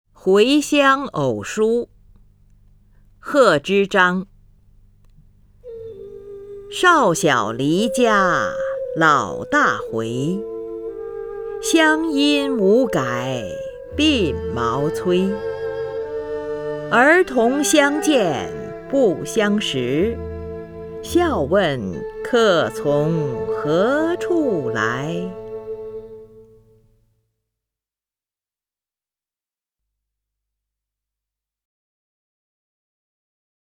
林如朗诵：《回乡偶书二首·其一》(（唐）贺知章)
名家朗诵欣赏 林如 目录